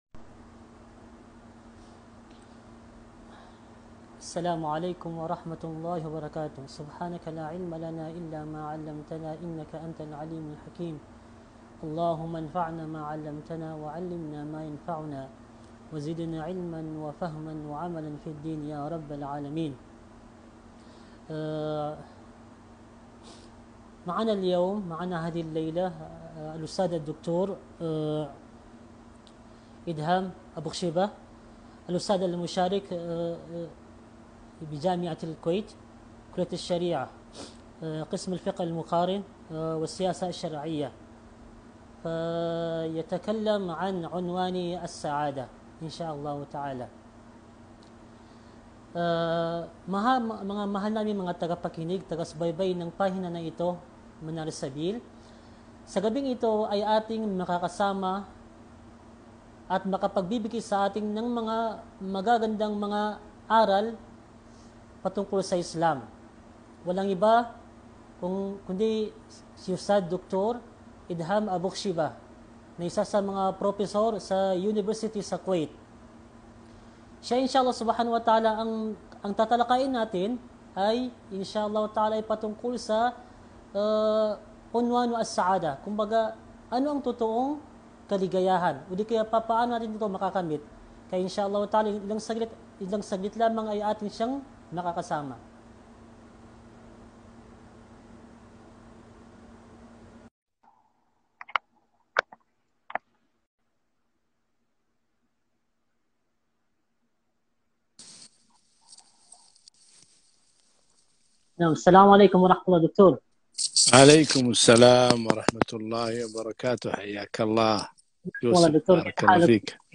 محاضرة - عنوان السعادة - مترجمة باللغة الفلبينيه